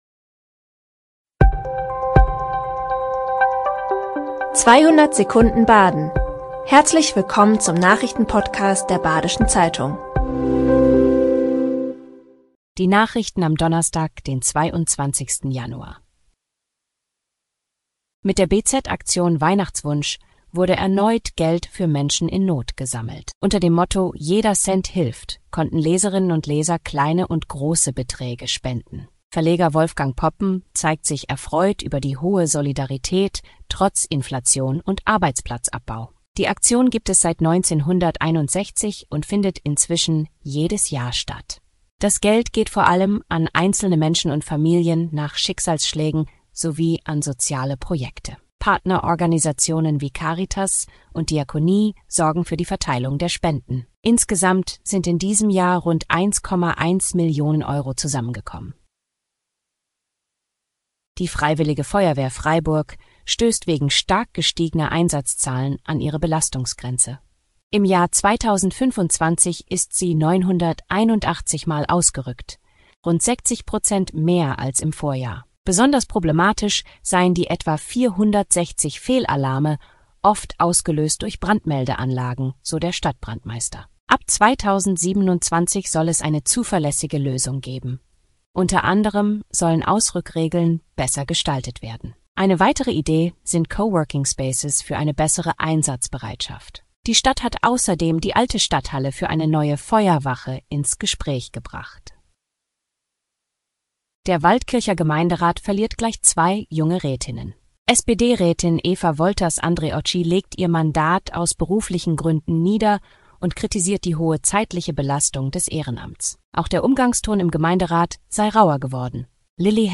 5 Nachrichten in 200 Sekunden.
Nachrichten